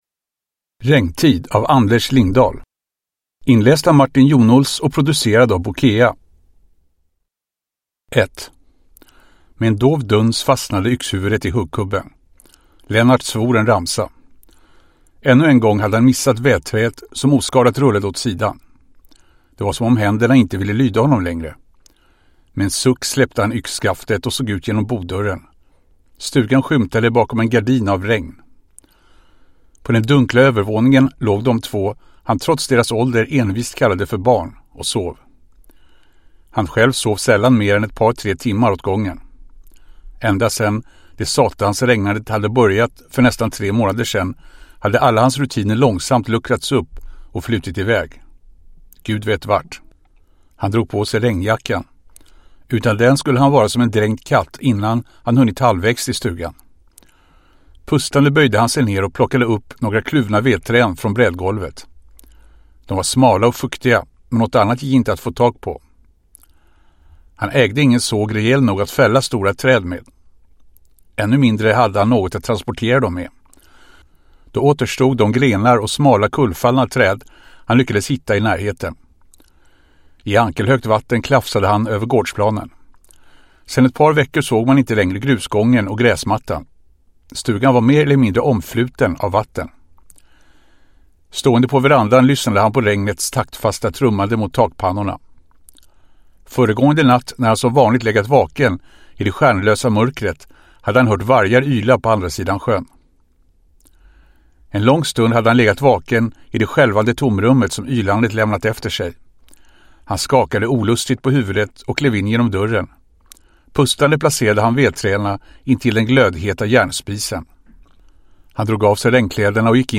Regntid (ljudbok) av Anders Lindahl